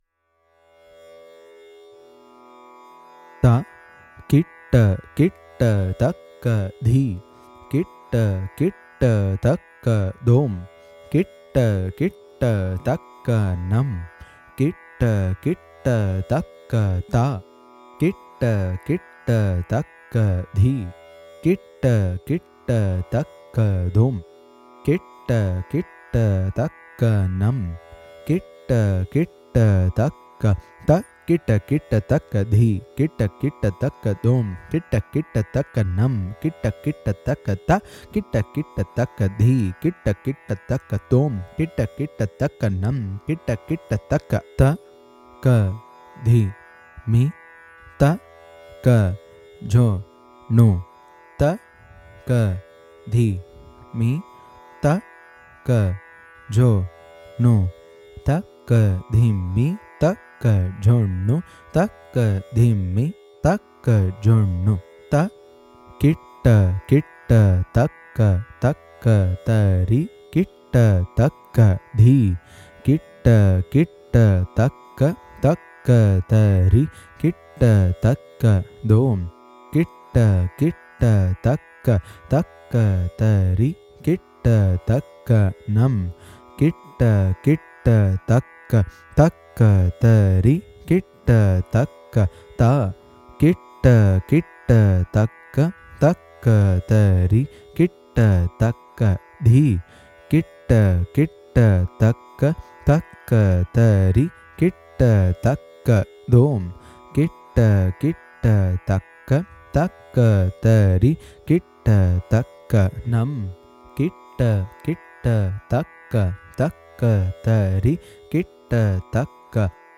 Please find below the Carnatic Vocal Recordings for MTB – SaPa Reading Skills.
• Reading Skills Recording - Carnatic Vocal - Grade 3
MTB_SaPa_Carnatic_Vocal_Grade-3_Reading_Skill.mp3